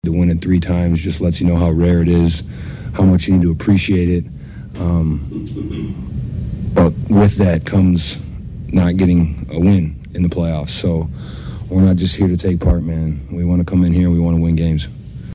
nws0583-tj-watt-we-wanna-win.mp3